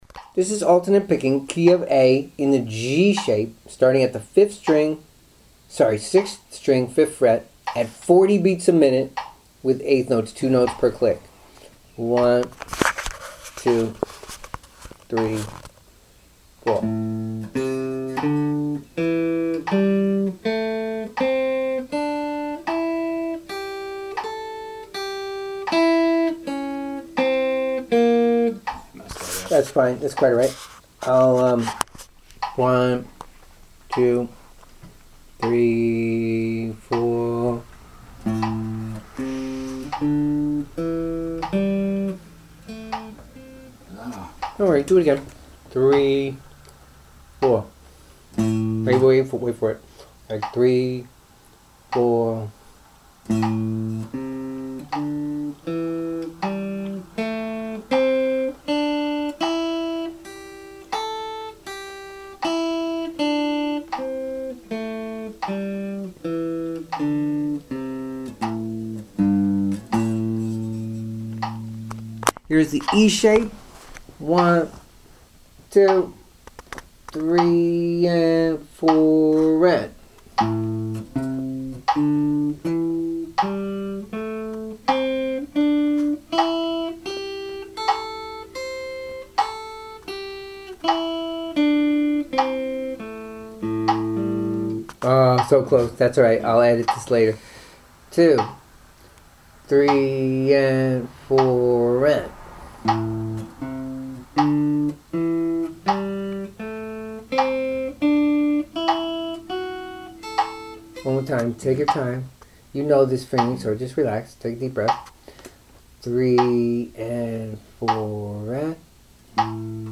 alternate-picking-on-am-pentatonic-scales.mp3